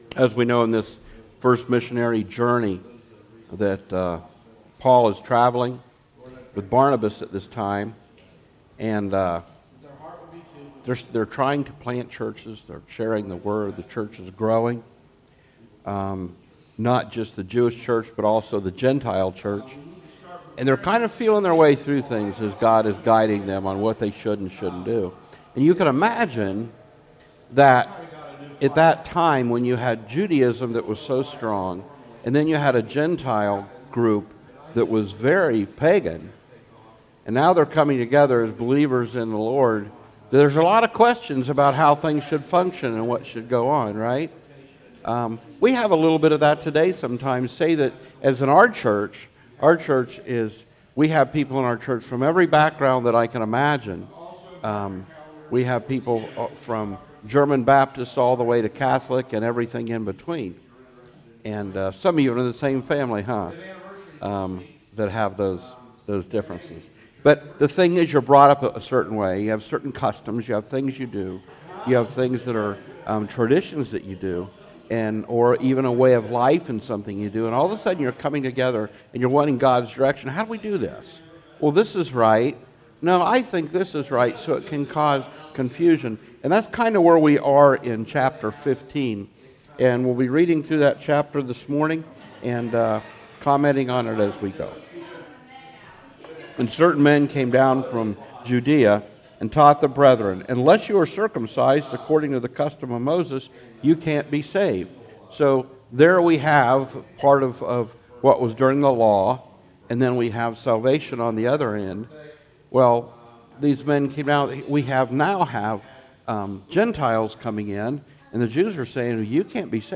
Sermons - Community Christian Fellowship